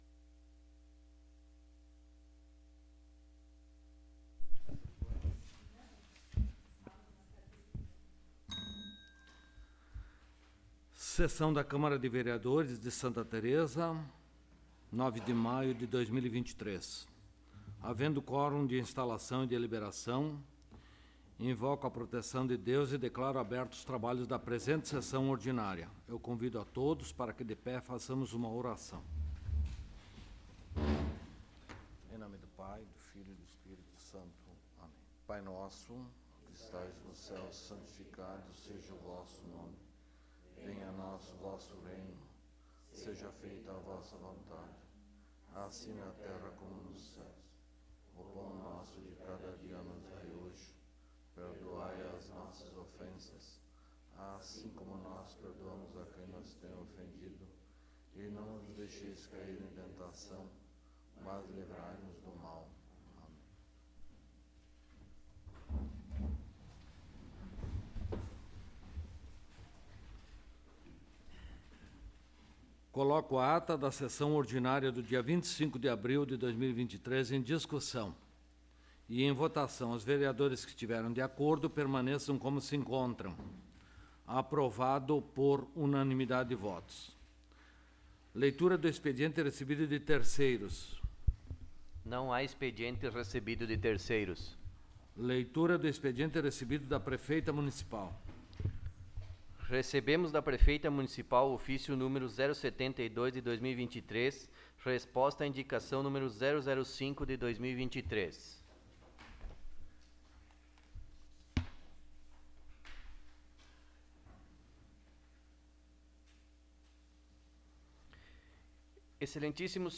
07° Sessão Ordinária de 2023